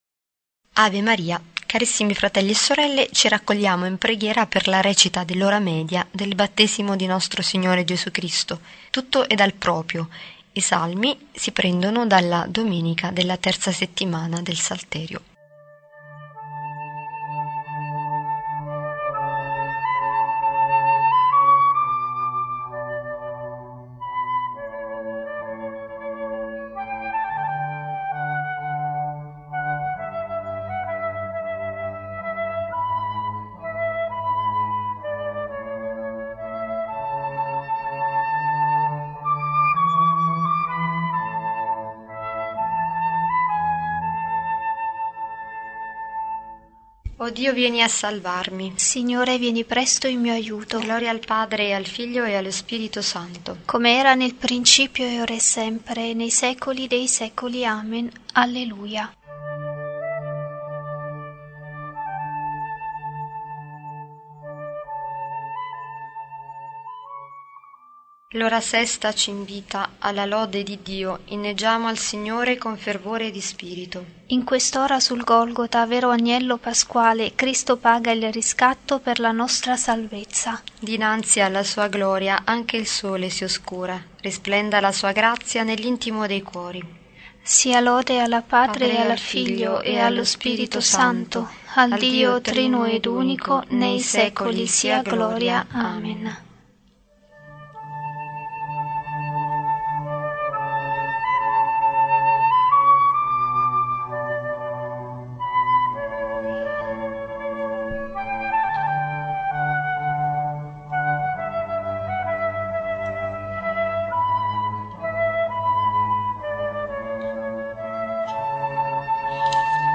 “01-13 O.M.Battesimo” di Suore Alassio.